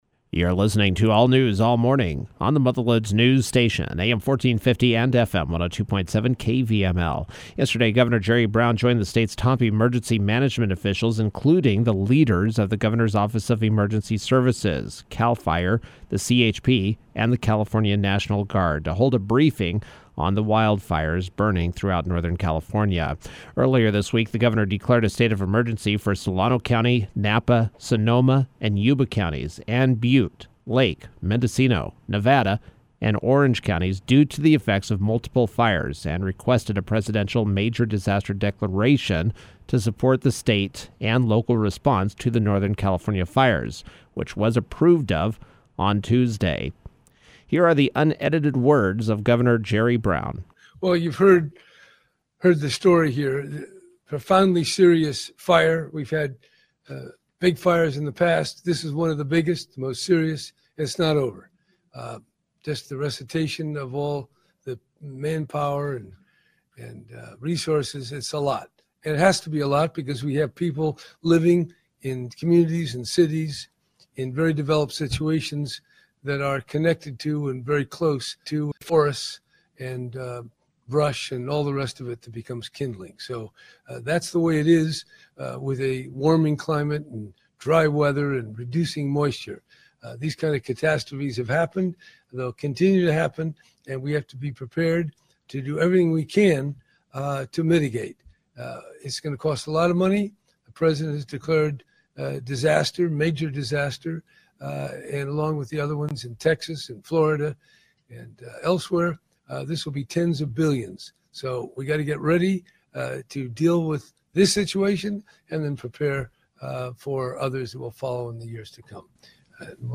California Governor Jerry Brown joined the state’s top emergency management officials to hold a briefing on wildfires yesterday at the State Operations Center in Mather, CA.